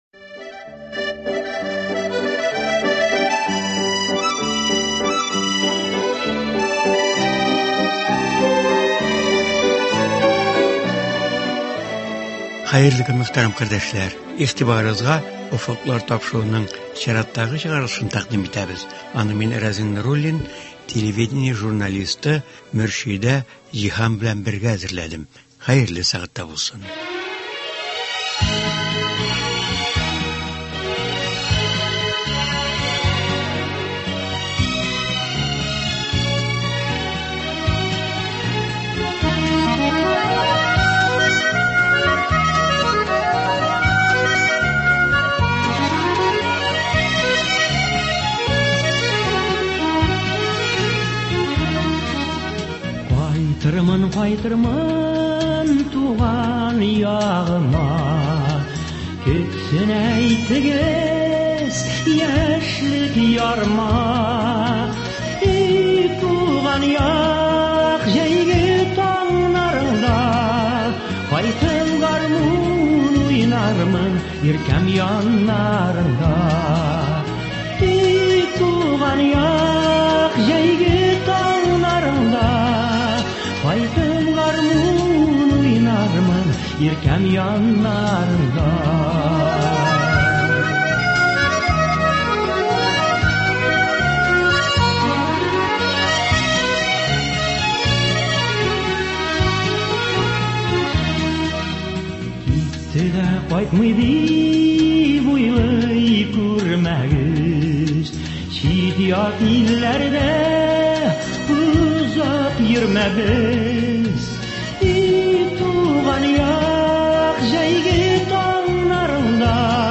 Тапшыруда шушы чарадан репортаж бирелә.